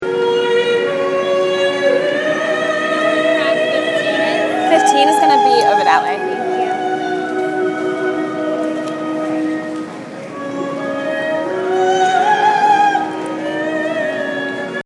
Location: Near McDonalds in Penn Station
Sounds heard: Woman singing opera, footsteps, woman asking me where Track 15 is, my telling her that it’s “over that way”
Opera.mp3